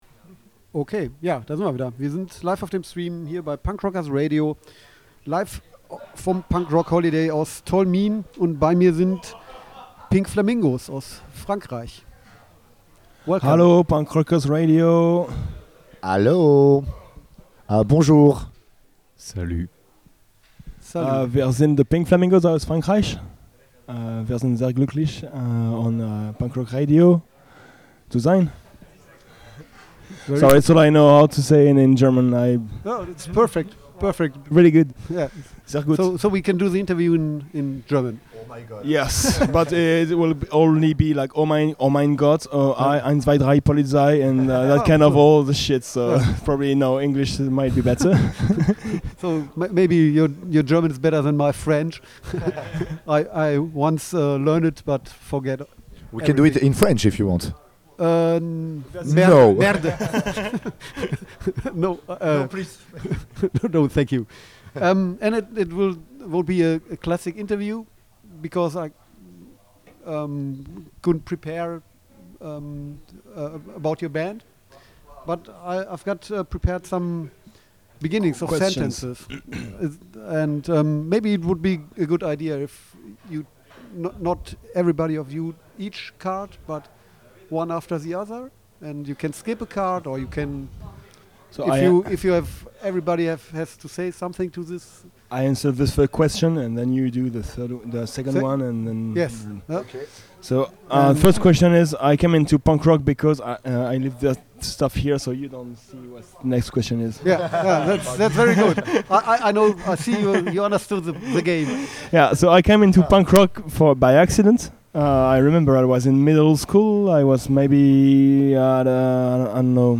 pink-flamingos-interview-punk-rock-holiday-1-8-mmp.mp3